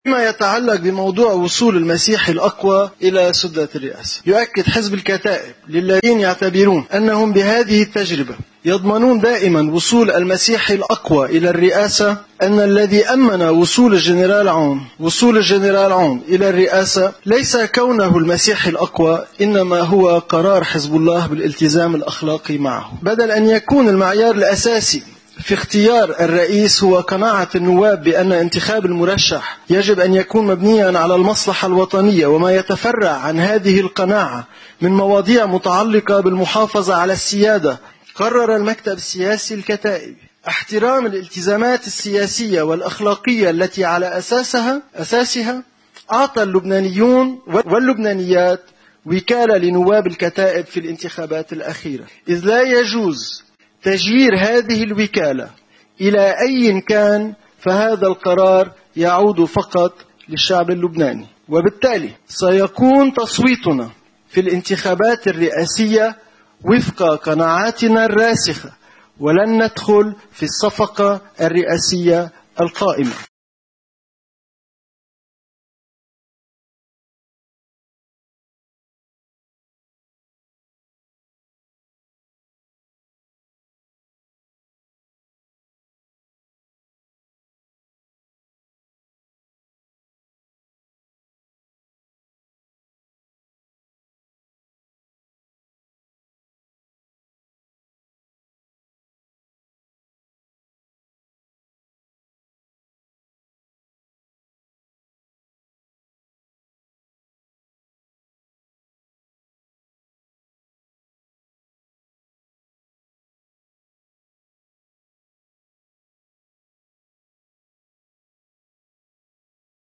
مقتطفات من المؤتمر الصحافي لرئيس حزب الكتائب سامي الجميّل: